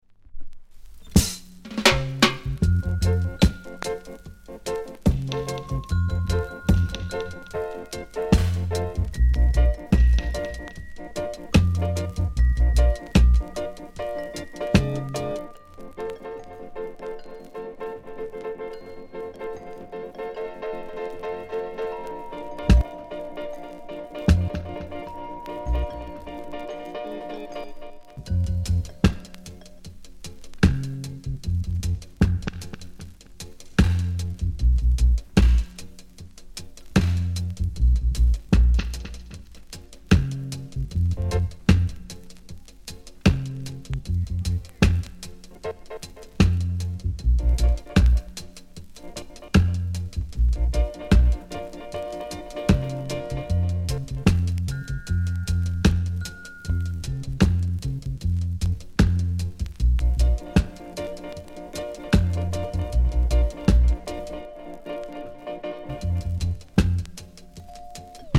ジャマイカ盤 7inch/45s。